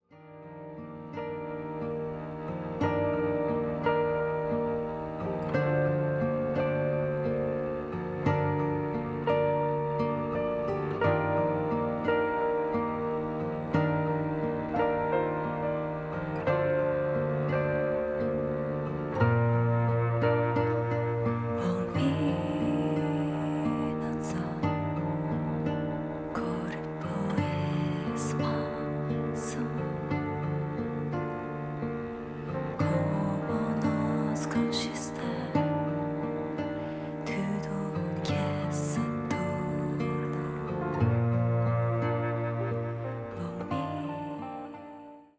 深みのあるギター・サウンド。囁くような歌声に呼び起こされるのはいつかの思い出。